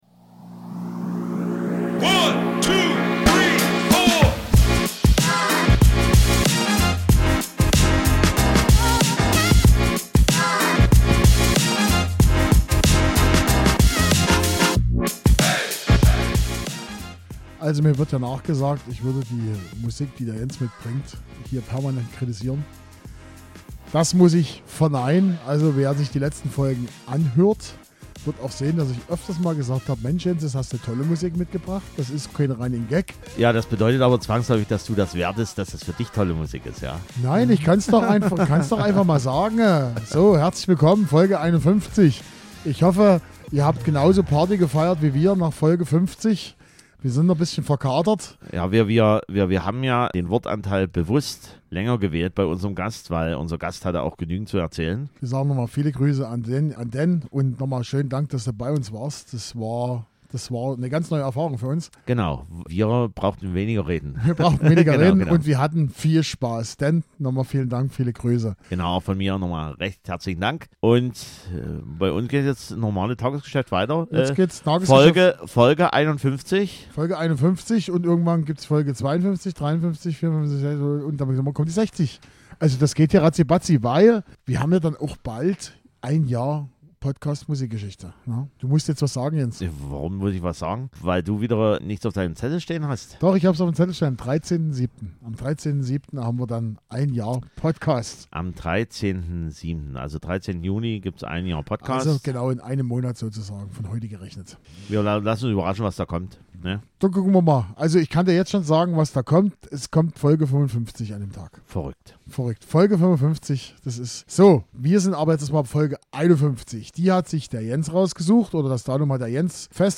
Wir haben Trance, Dance Pop, Summermusic und Indie-Rock dabei. Außerdem reden wir am Ende noch ein bisschen über die Heavy-Rotation im Radio und deren Folgen.